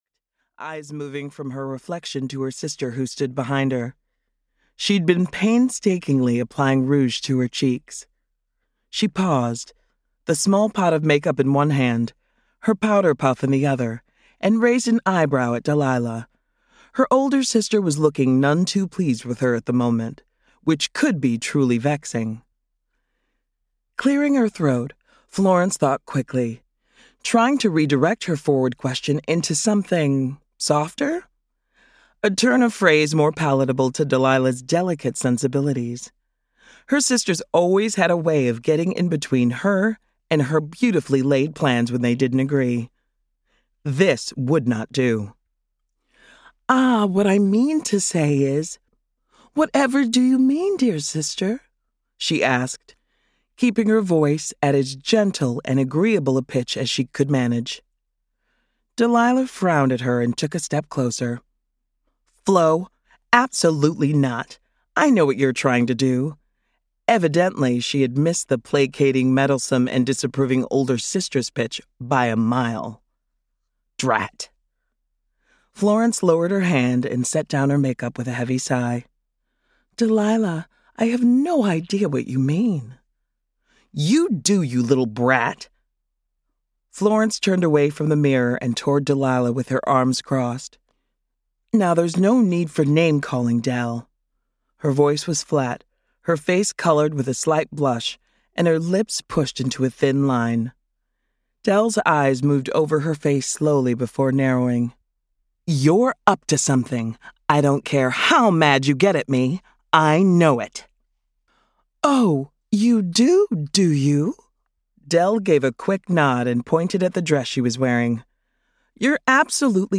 Three To Love (EN) audiokniha
Ukázka z knihy